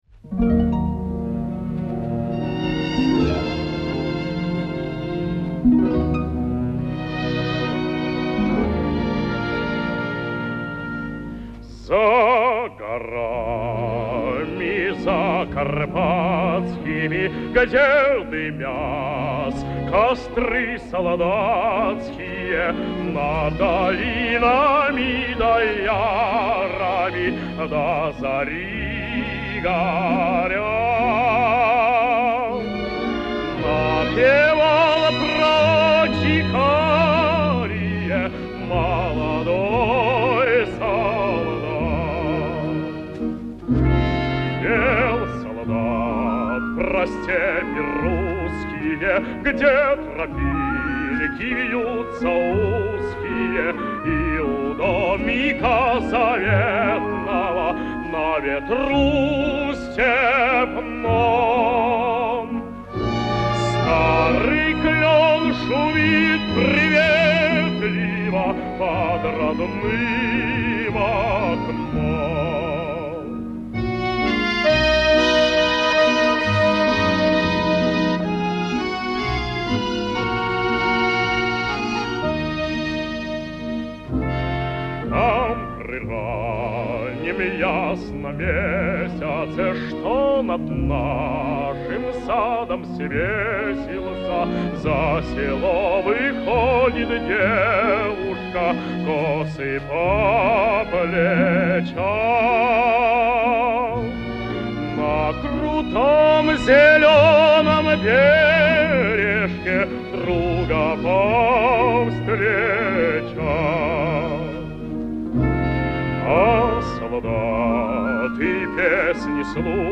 Запись с магнитофонной ленты